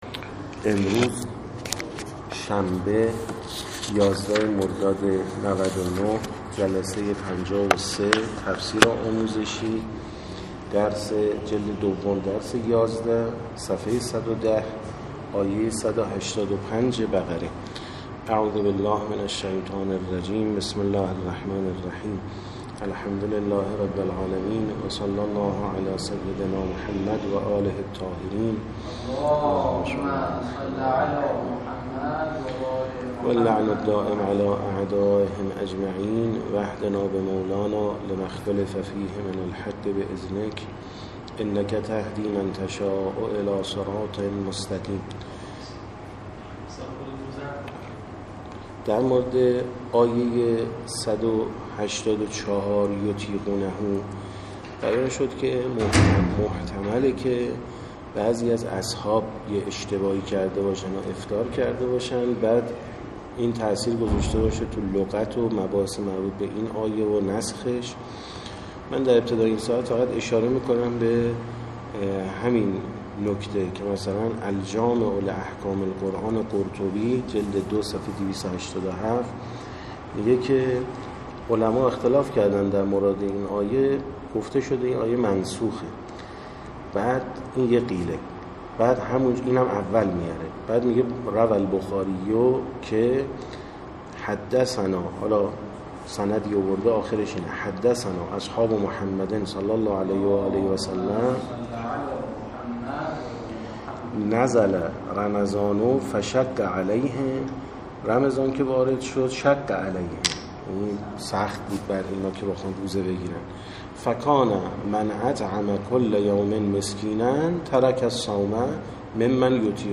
53درس11ج2تفسیرآموزشی-ص110تا113-آیه185بقره.MP3